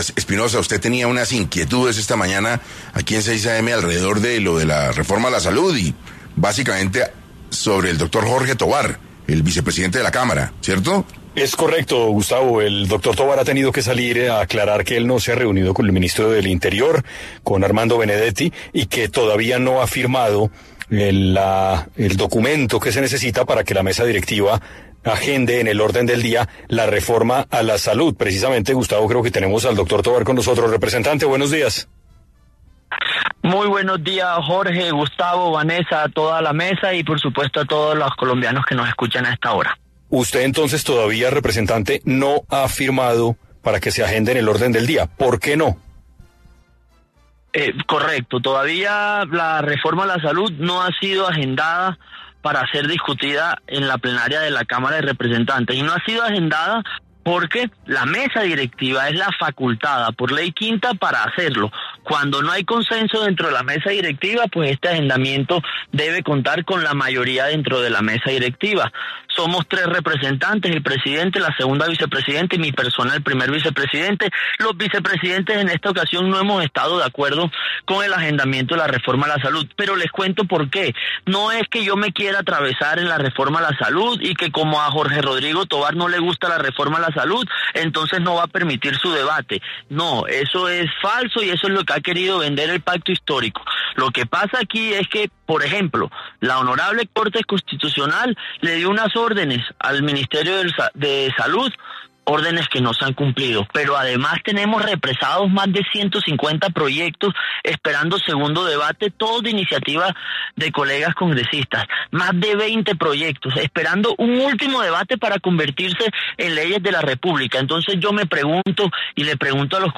Jorge Tovar, representante a la Cámara y vicepresidente de la corporación, dijo en 6AM de Caracol Radio que no ha firmado el documento necesario para que la mesa directiva agende el debate de la reforma a la salud del gobierno del presidente Gustavo Petro.